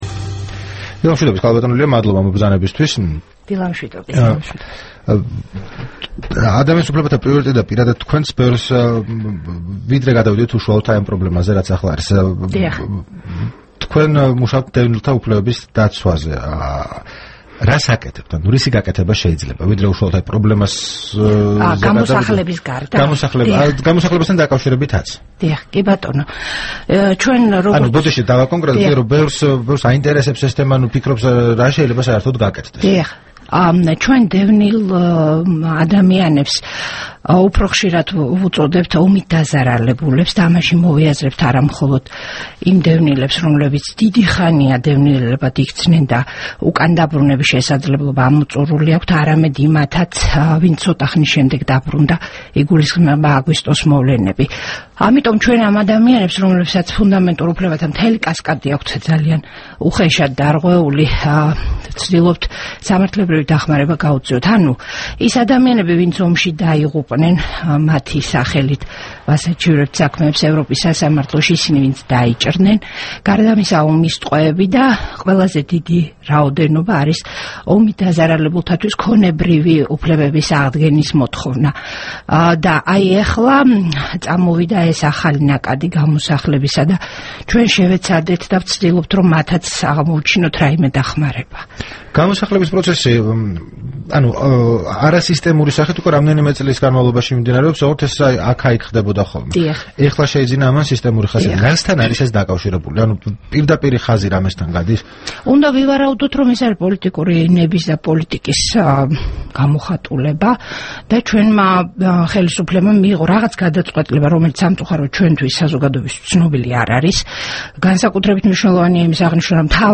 მოისმინეთ: საუბარი ლია მუხაშავრიასთან